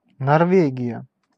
Ääntäminen
Ääntäminen Haettu sana löytyi näillä lähdekielillä: suomi Käännös Ääninäyte Erisnimet 1. Норвегия {f} (Norvegija) Määritelmät Erisnimet Pohjois-Euroopassa , Suomen , Ruotsin ja Tanskan naapurissa sijaitseva valtio , Norjan kuningaskunta . suomalainen sukunimi Esimerkit Norjan pääkaupunki on Oslo.